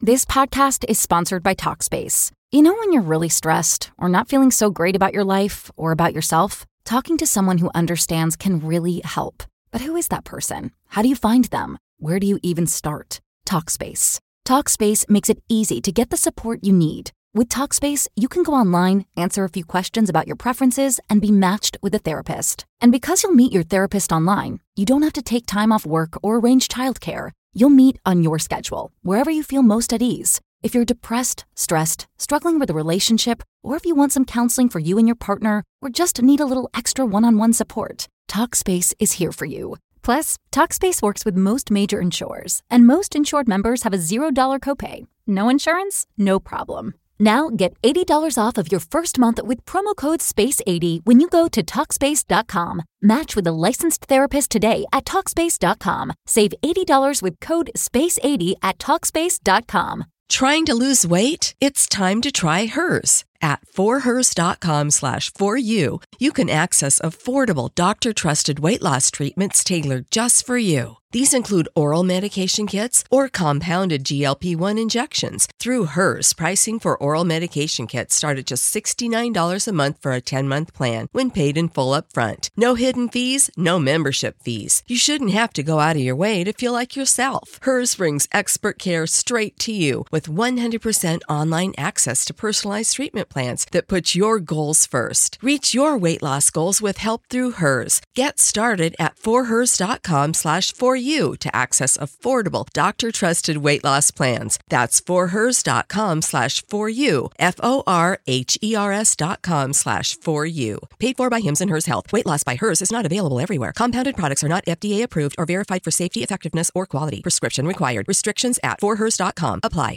80hz - Gamma Binaural Beats for ADHD